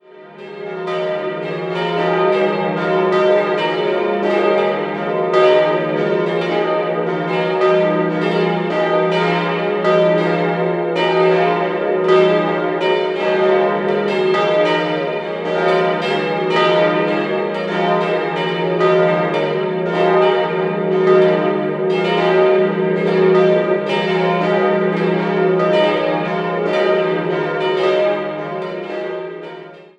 4-stimmiges Geläut: es'-f'-as'-b' Die Glocken wurden 1974 von Rudolf Perner in Passau gegossen. Aus statischen Gründen fanden sie nicht, wie eigentlich geplant, im Hauptturm Platz, sondern in einem einem niedrigen Glockenträger neben der Kirche.